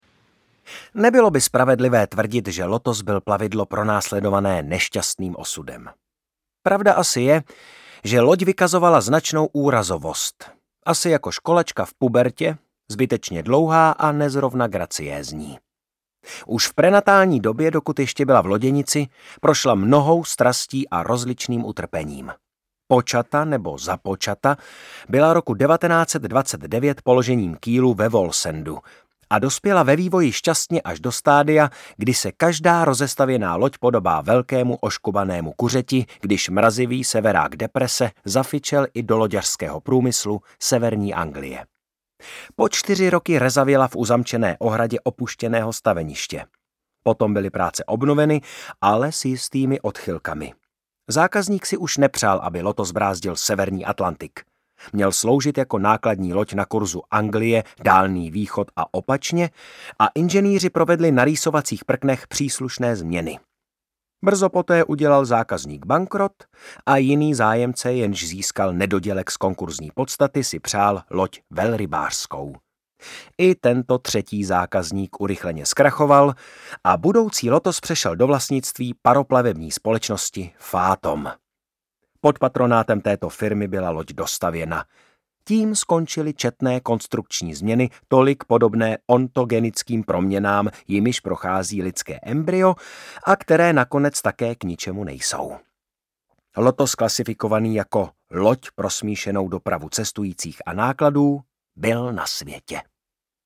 Audiokniha
Čte: Martin Písařík